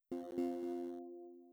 cancel.wav